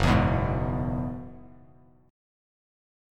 F#7sus2sus4 chord